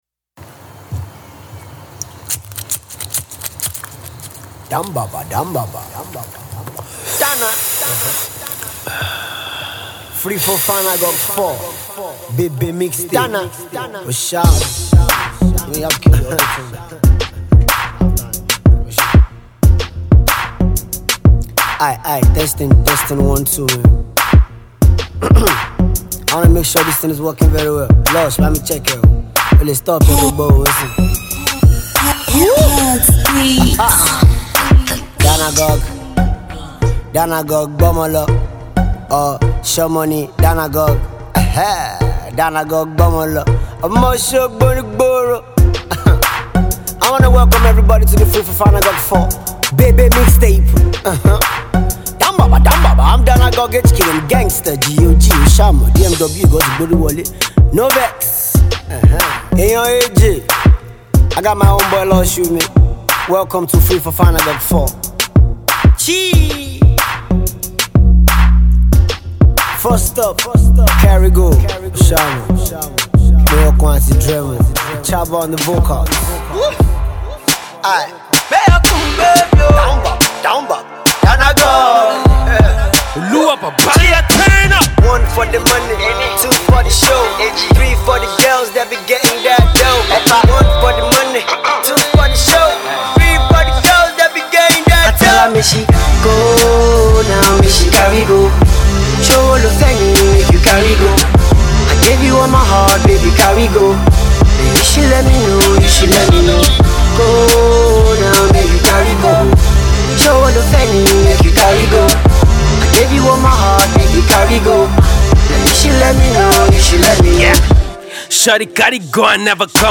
He keeps it short and jiggy.